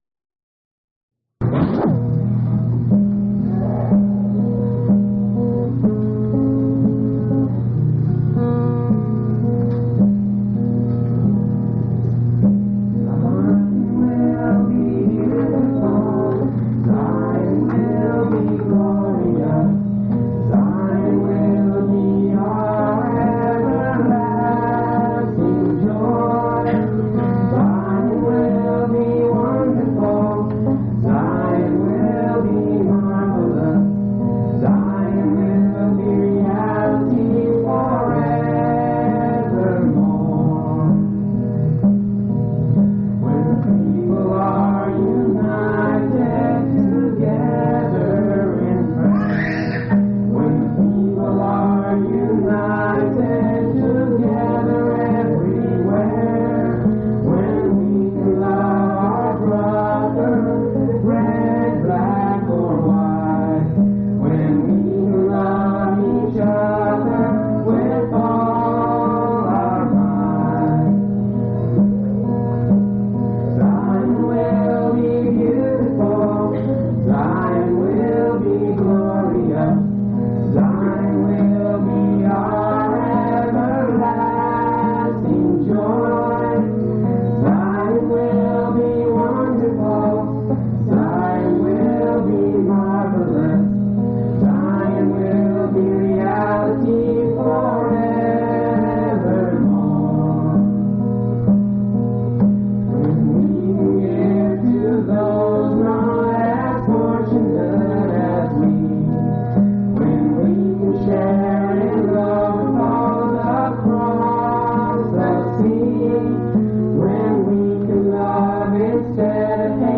4/7/1982 Location: Temple Lot Local (Conference) Event: General Church Conference